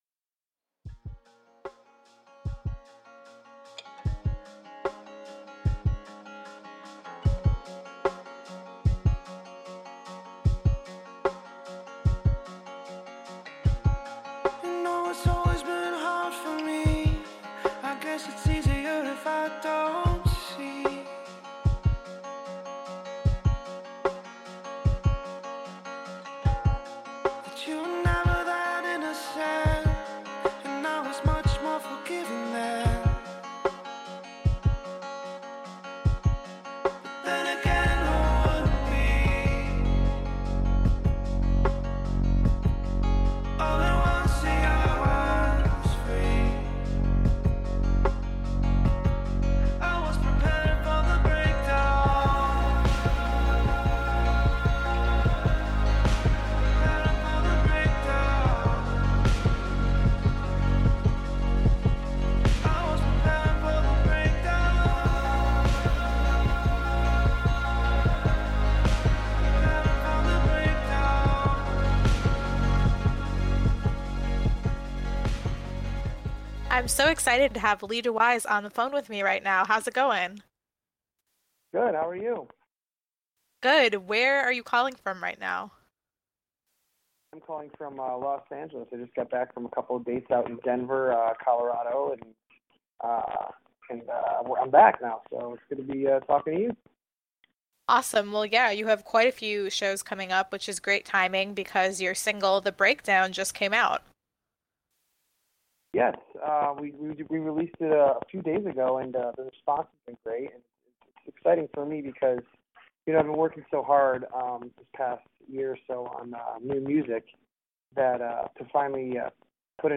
Lee Dewyze Interview